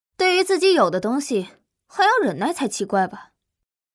Game VO
Her voice is a symphony of strength and elegance—blending tomboyish resilience with mature sophistication. It exudes undeniable authority, like a strategist commanding the scene: independent, assertive, and fully empowered.